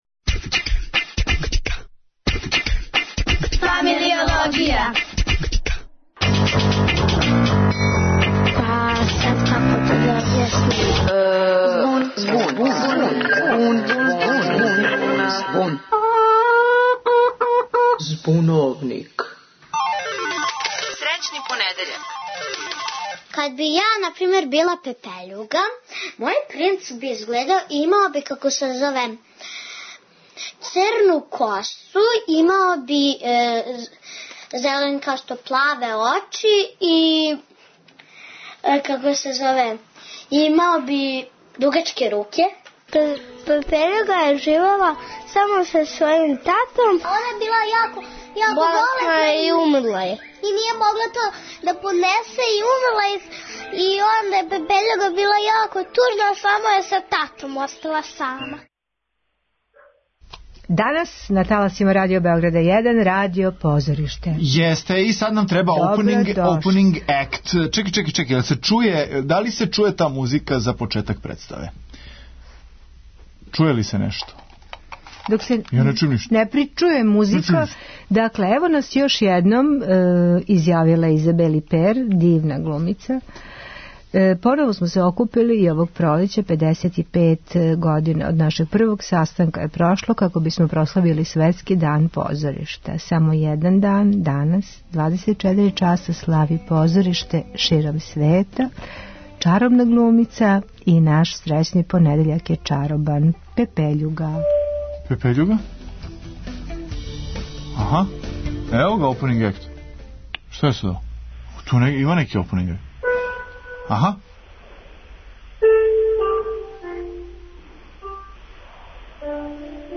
Данас на таласима Радио Београда 1, радио позориште!